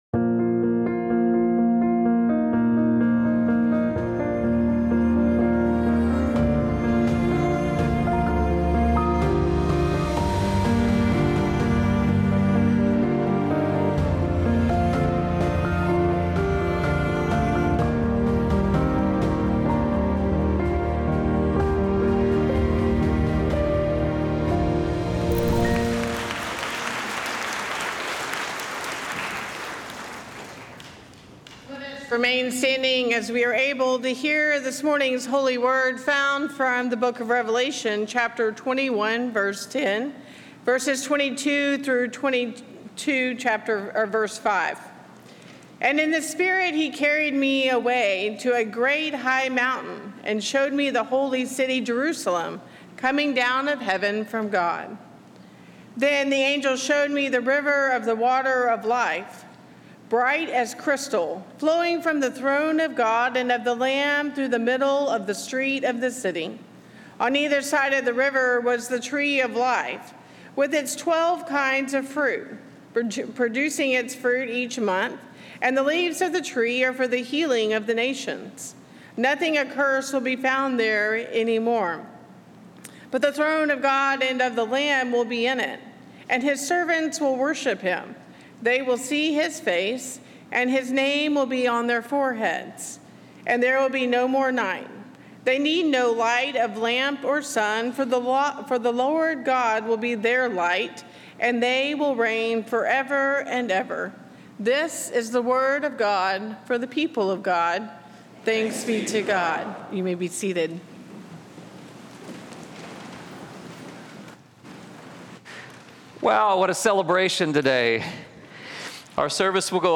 This sermon, delivered during a celebratory Sunday service, reflects on the themes of resurrection, renewal, and mission.